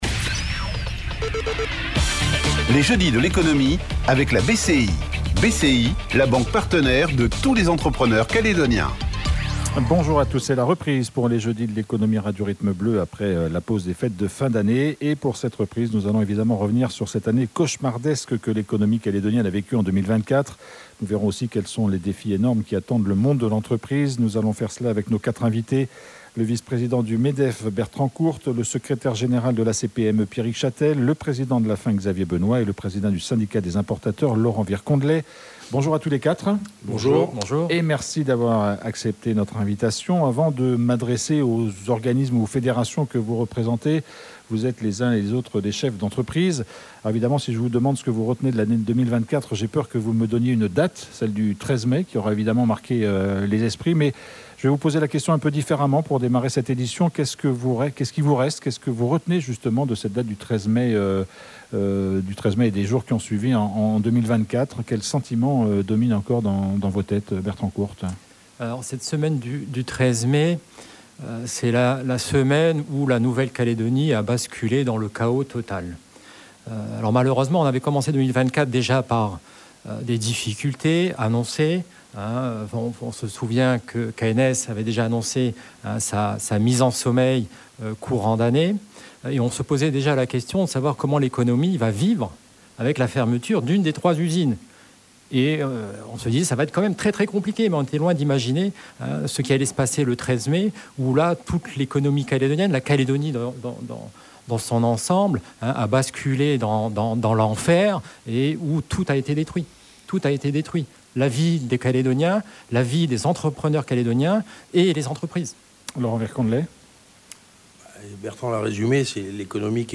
Aujourd’hui, les jeudis de l’économie font leur retour sur l’antenne de Radio Rythme Bleu. Pour cette reprise, nous somme revenu sur l’année cauchemardesque que l’économie Calédonienne a vécu en 2024.